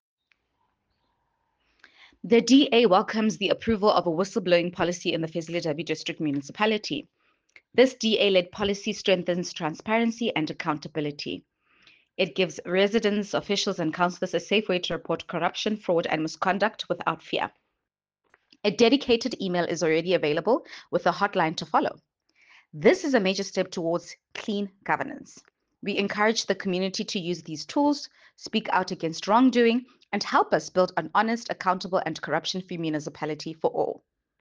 Sesotho soundbites by Cllr Mbali Mnaba and